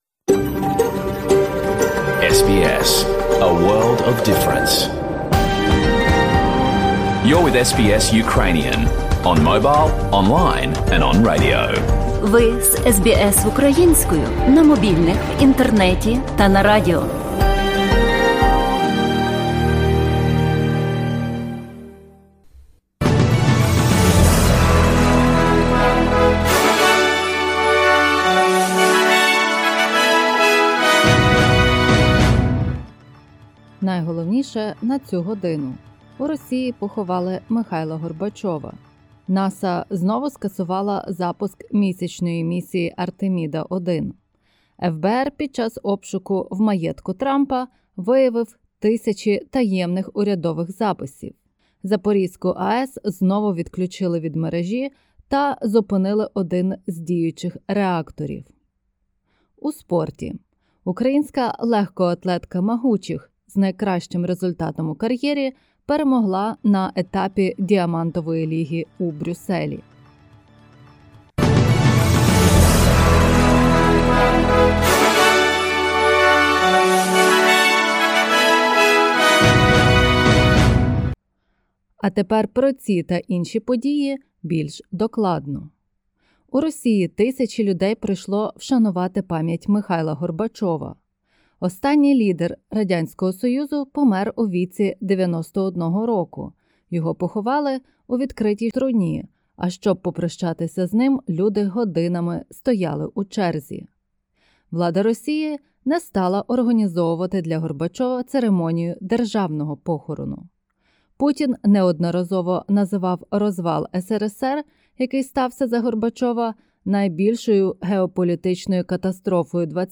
In this bulletin